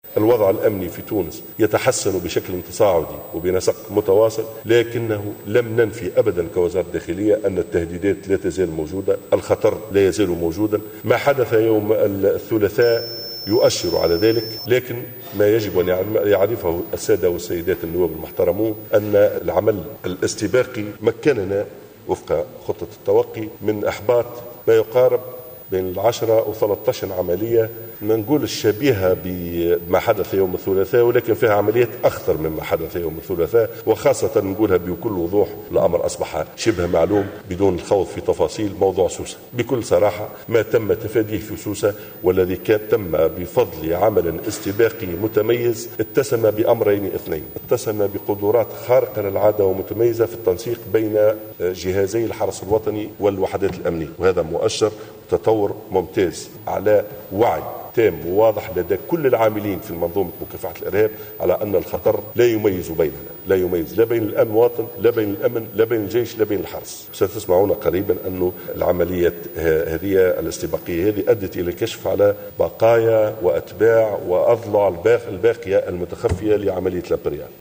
أكد وزيرُ الداخلية ناجم الغرسلي في مُداخلة له أمام نوّاب مجلس الشعب أنّ العمل الاستباقي مكّن من إحباط ما بيْن 10 و13 عملية إرهابية أخطر من عملية تونس العاصمة التي استهدفت حافلة أعوان الأمن الرئاسي، في شارع محمد الخامس في العاصمة الثلاثاء الماضي، وأودت بحياة 12 أمنيا.